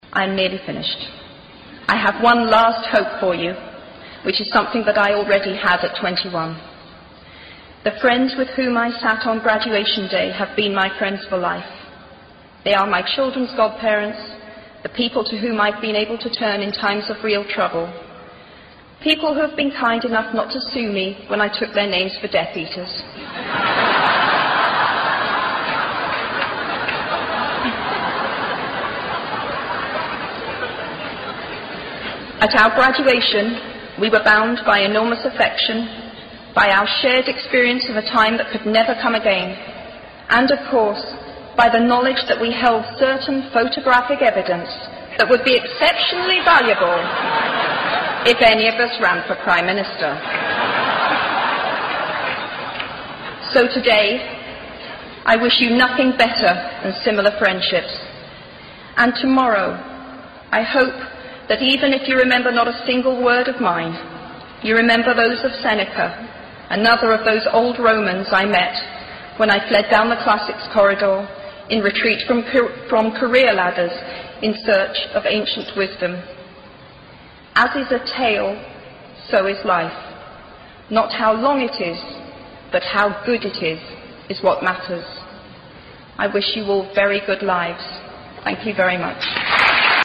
偶像励志英语演讲 29:失败的好处和想象的重要性(11) 听力文件下载—在线英语听力室
在线英语听力室偶像励志英语演讲 29:失败的好处和想象的重要性(11)的听力文件下载,《偶像励志演讲》收录了娱乐圈明星们的励志演讲。